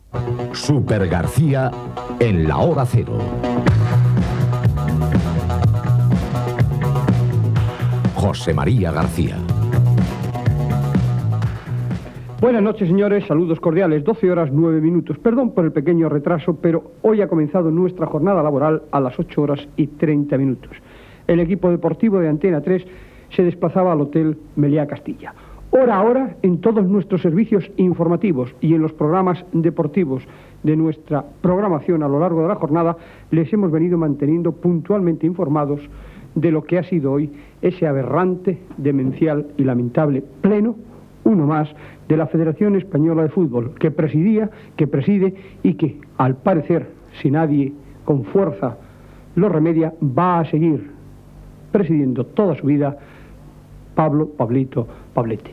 Careta del programa i presentació del programa dedicat al ple de la Federación Española de Fútbol, celebrada a Madrid aquell dia
Esportiu